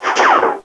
LASER2.WAV